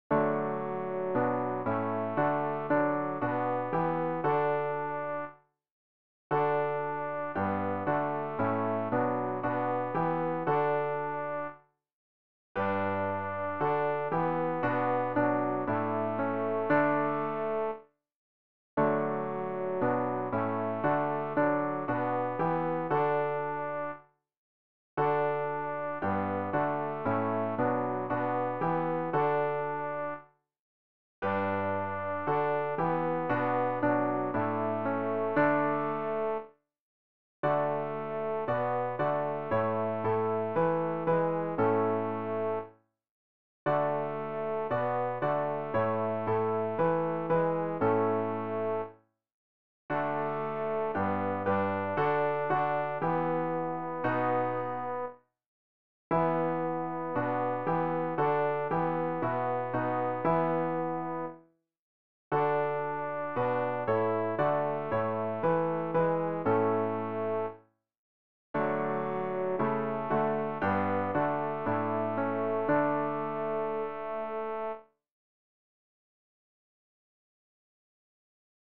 tenor-rg-044-erhebt-sich-gott-in-seiner-macht.mp3